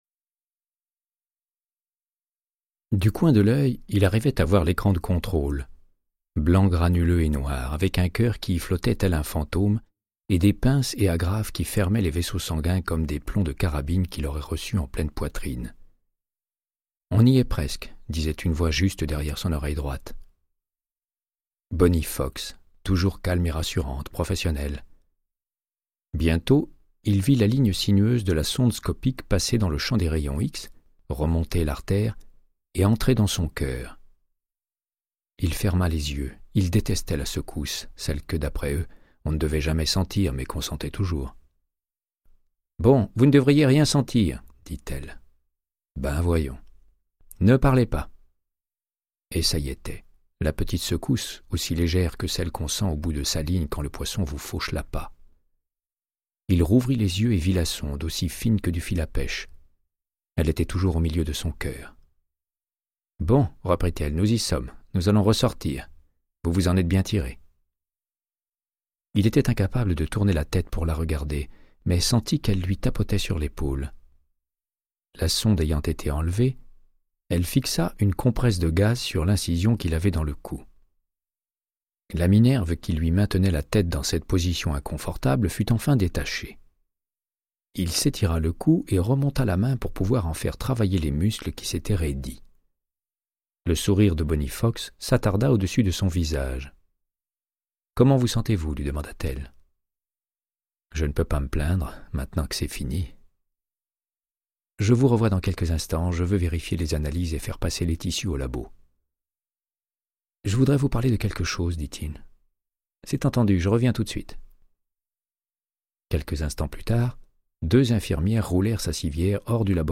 Audiobook = Créance de sang, de Michael Connellly - 04